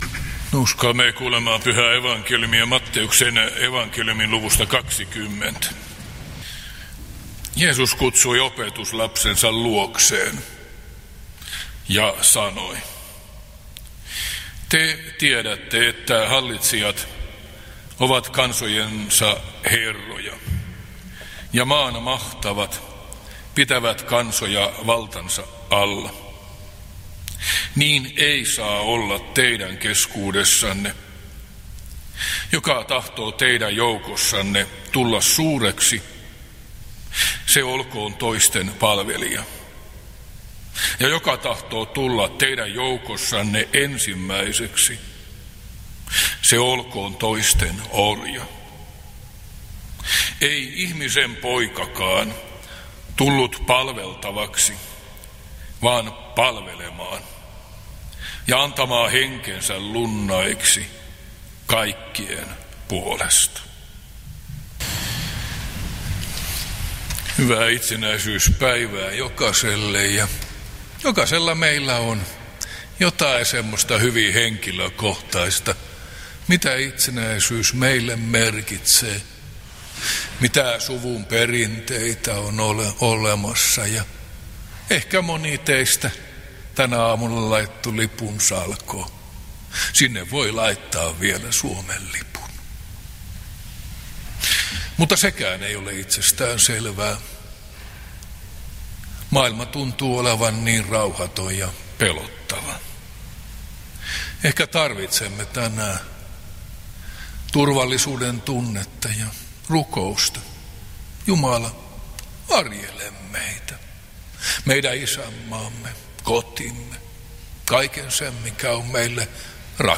Teuvalla itsenäisyyspäivänä Tekstinä Matt. 20:25–28